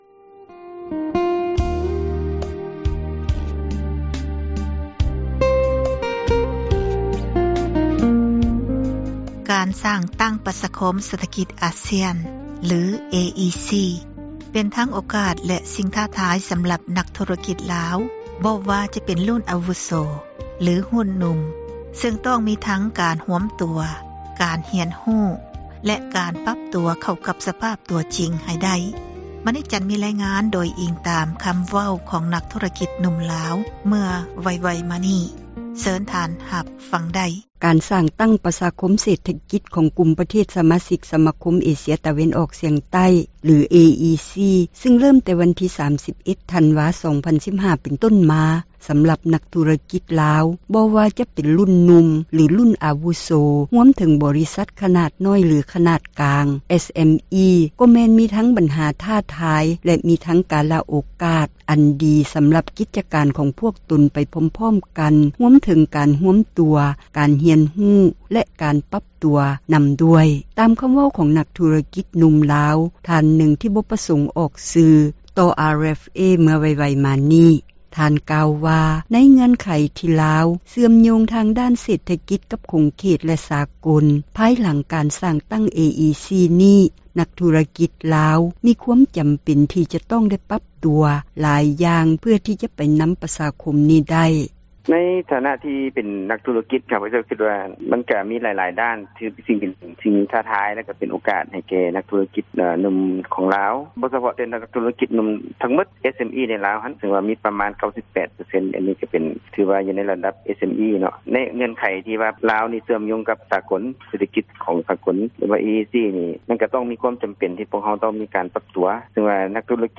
ຕາມຄໍາເວົ້າ ຂອງ ນັກ ທຸຣະກິດ ໜຸ່ມລາວ ທ່ານນຶ່ງ ທີ່ ບໍ່ປະສົງ ອອກຊື່ ຕໍ່ RFA ເມື່ອໄວໆມານີ້. ທ່ານກ່າວວ່າ ໃນເງື່ອນໄຂ ທີ່ລາວ ເຊື່ອມໂຍງ ທາງດ້ານ ເສຖກິດ ກັບຂົງເຂດ ແລະ ສາກົລ ພາຍຫລັງ ການສ້າງຕັ້ງ AEC ນີ້ ນັກ ທຸຣະກິດ ລາວ ມີຄວາມຈໍາເປັນ ທີ່ຈະຕ້ອງໄດ້ ປັບຕົວ ຫລາຍຢ່າງ ເພື່ອ ທີ່ຈະໄປນຳ ປະຊາຄົມ ນີ້ໄດ້.